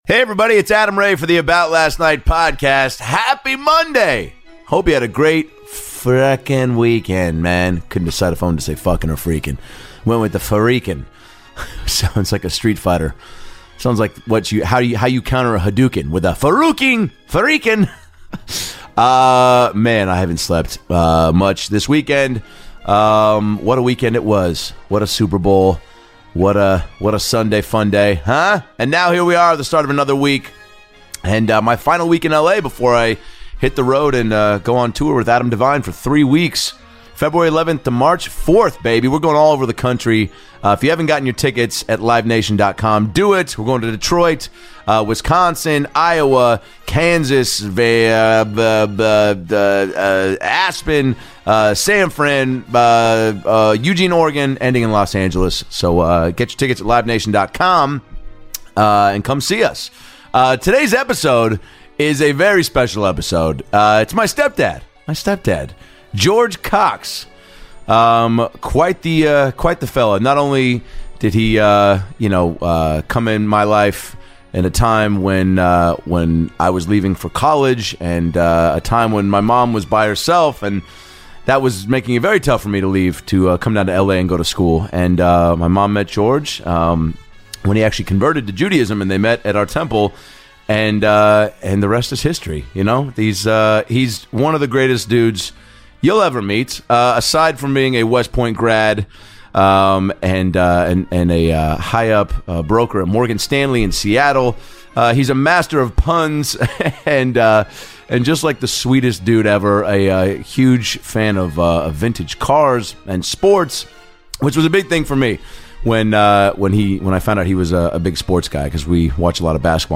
This episode has laughs, inspiring perspectives, and a love story that'll make your heart warm.